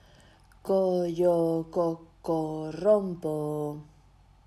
- as mesmas frases, farémolas con distintos ritmos sen cantar; un exemplo será o que segue, pero teredes que inventar un diferente e farémolo xuntas/os: